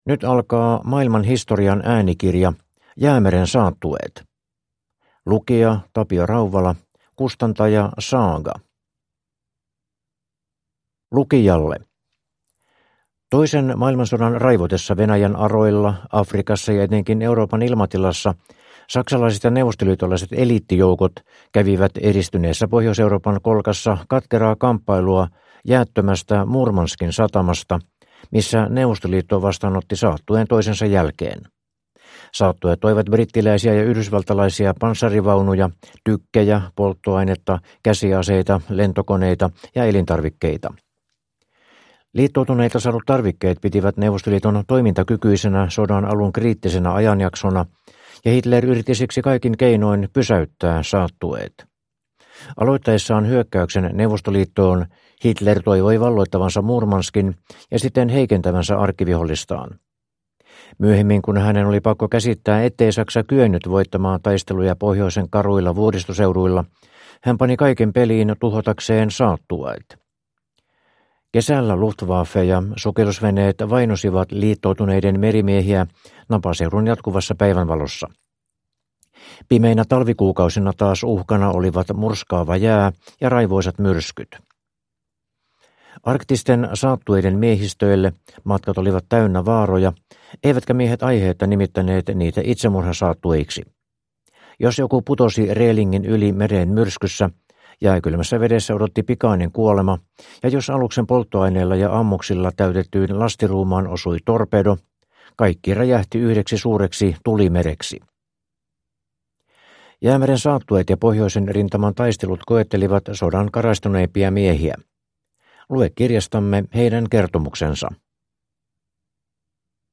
Jäämeren saattueet (ljudbok) av Maailman Historia